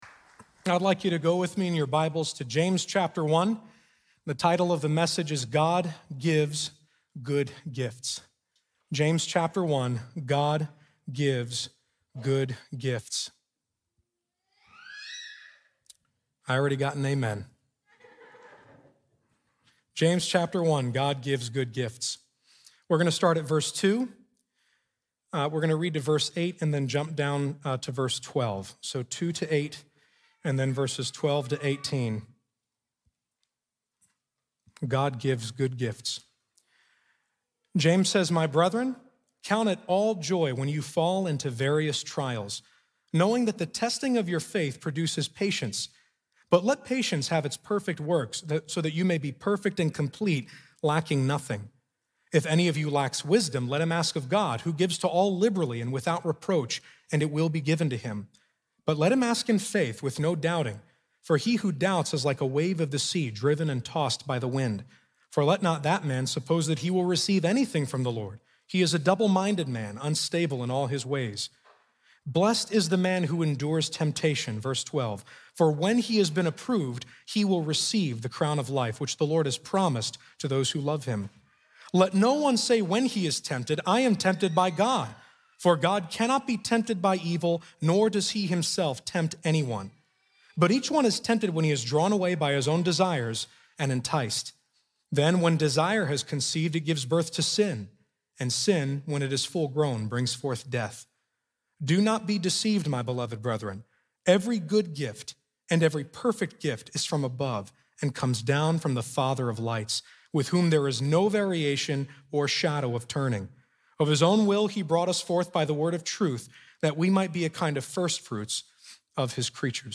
God Gives Good Gifts | Times Square Church Sermons